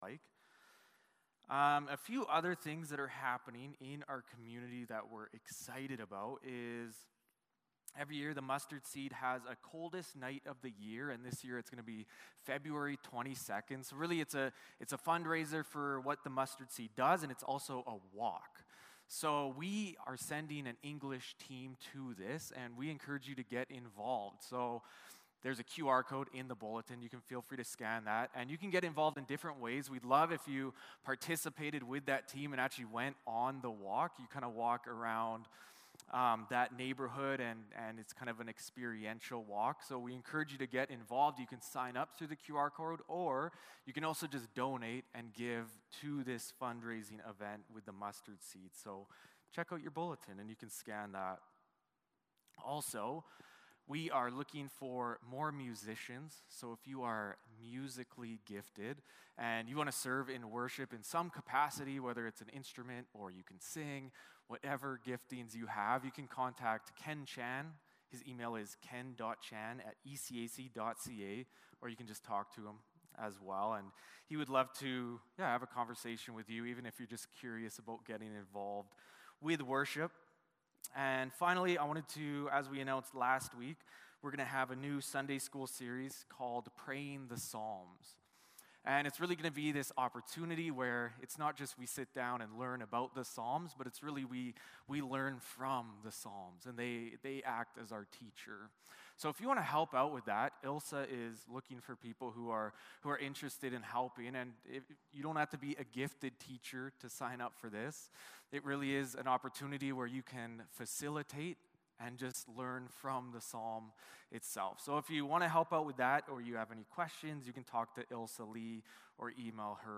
2 Timothy 2:14-26 Service Type: Sunday Morning Service Passage